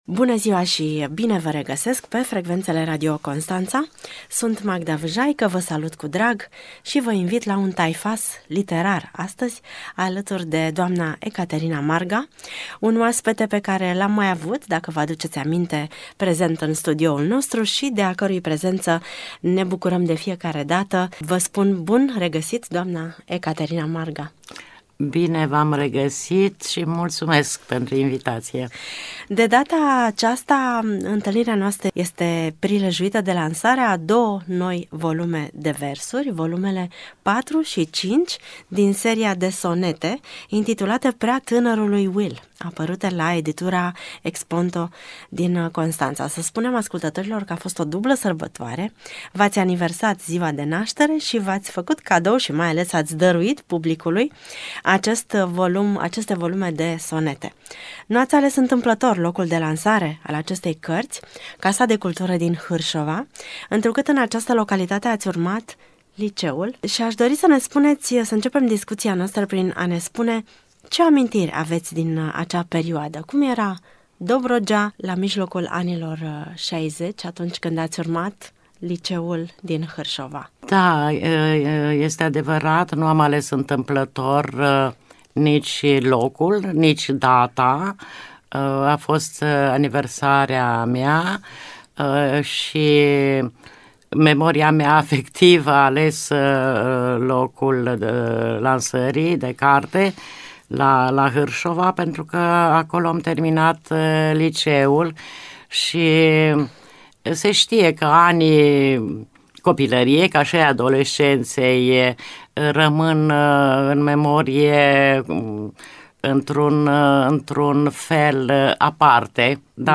Un dialog despre pasiunea pentru poezie și despre actualul peisaj literar românesc și dobrogean.